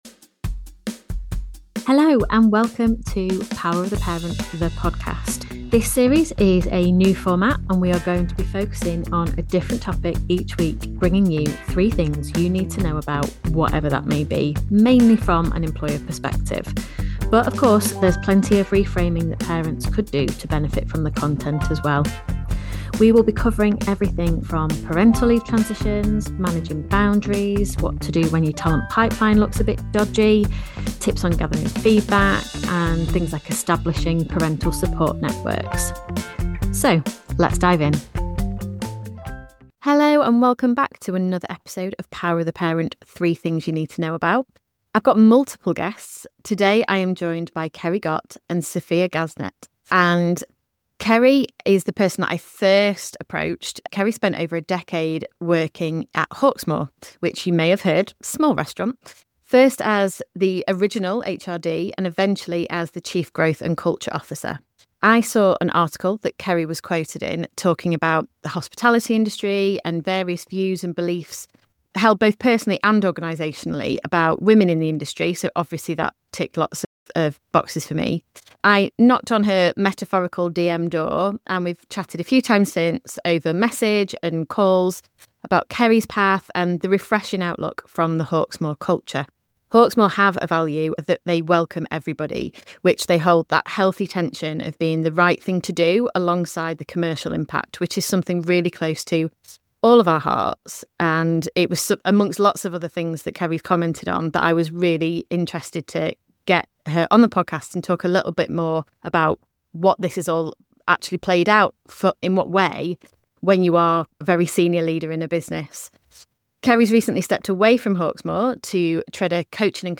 Two guests this week!